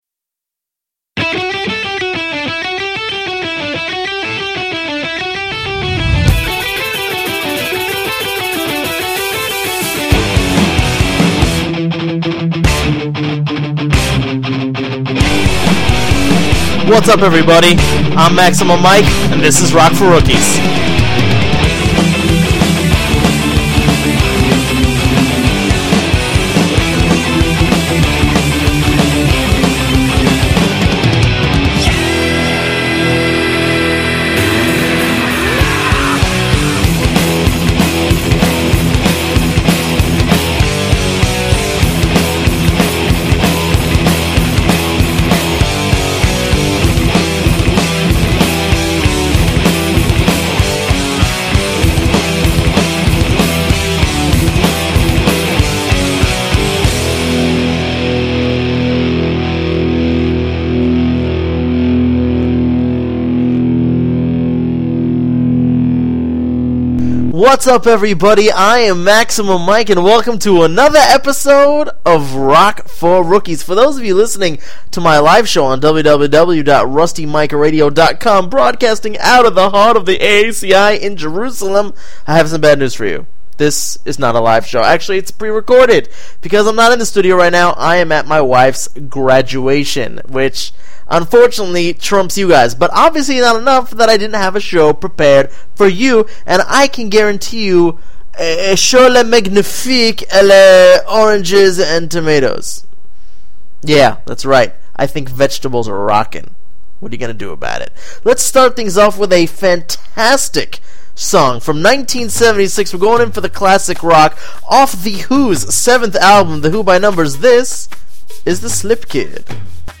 This Week: Not live but just as good!!